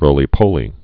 (rōlē-pōlē)